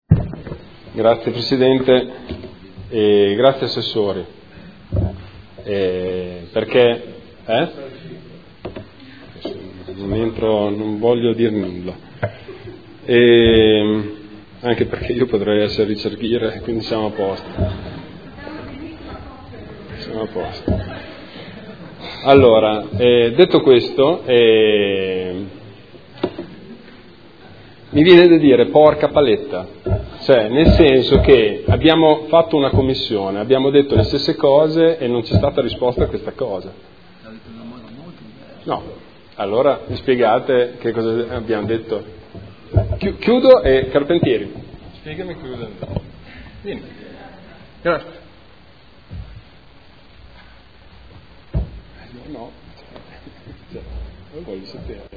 Marco Bortolotti — Sito Audio Consiglio Comunale
Seduta del 30/03/2015. Proposta di deliberazione: Variante al Piano Strutturale Comunale (PSC) – Area ubicata tra Tangenziale, strada Ponte Alto e strabello Anesino – Zona elementare n. 2050 Area 01 – Controdeduzioni alle osservazioni e approvazione ai sensi dell’art. 32 della L.R. 20/2000 e s.m.i. Dibattito